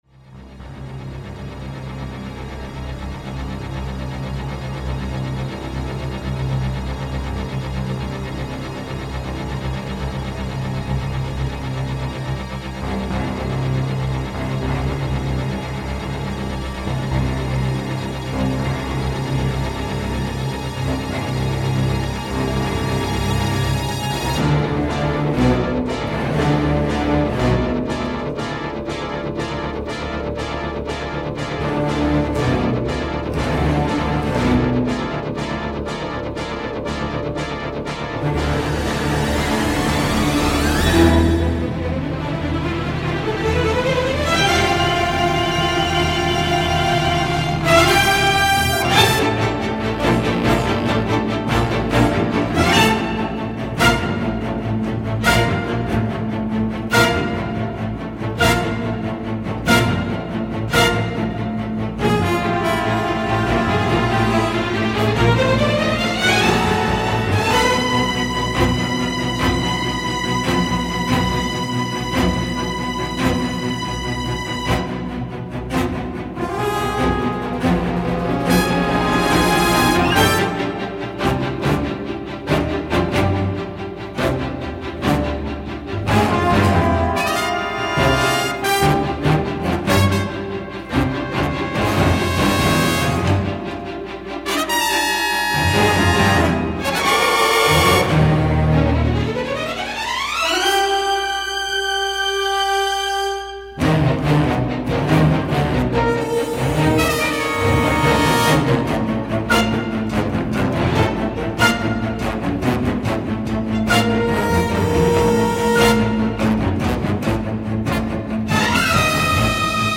Le travail de restauration sonore est exemplaire.